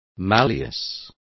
Complete with pronunciation of the translation of malleuses.